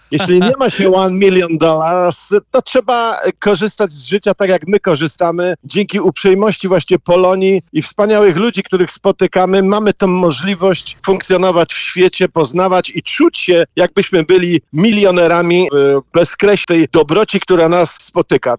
Na antenie Radia Deon Chicago lider grupy, kompozytor, instrumentalista i wokalista – Grzegorz Stróżniak, oraz Marta Cugier – wokalistka pisząca teksty oraz menager, zapowiadają swoje przybycie do Wietrznego Miasta.